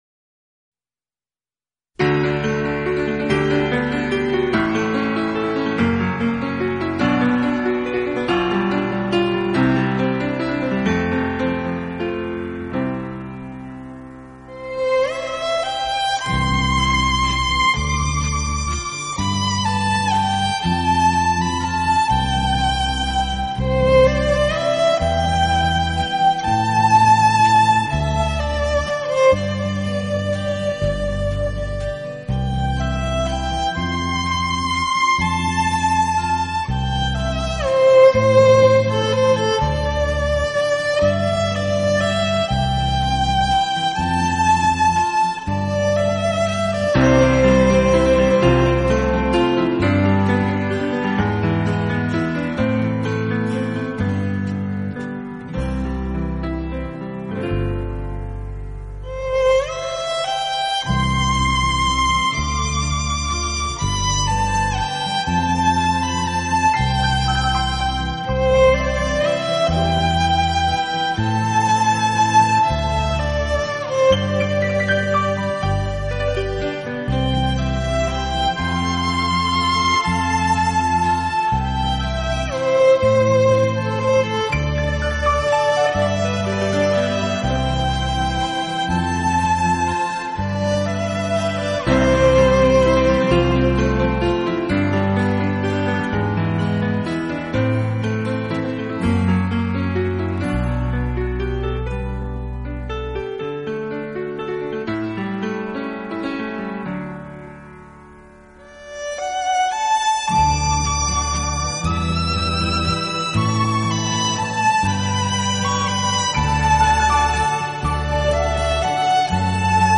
Genre ...........: Instrumental
大家一看见这张专辑的封面不难猜出，这就是一张很纯净的轻音乐专辑，就是
以小提琴为主旋律，其他音乐为辅。